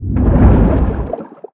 Sfx_creature_shadowleviathan_seatruckattack_loop_water_os_02.ogg